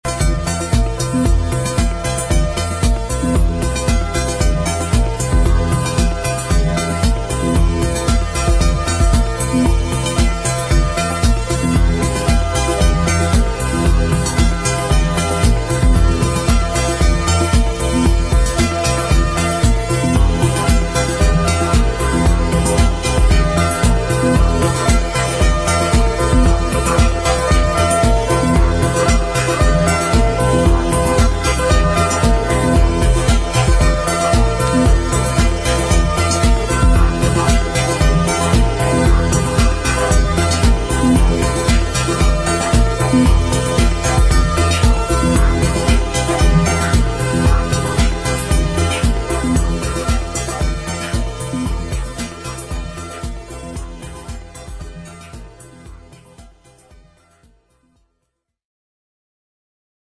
cosmic-electro-disco band